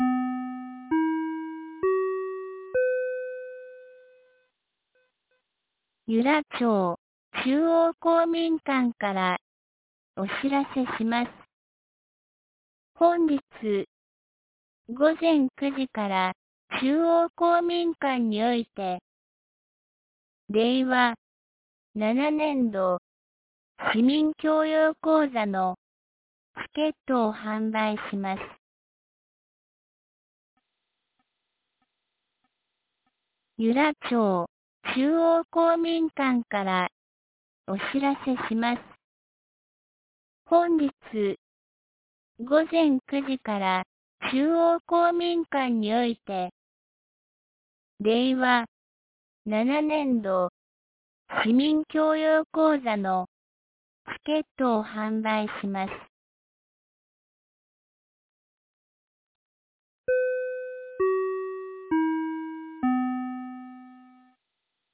2025年05月22日 07時51分に、由良町から全地区へ放送がありました。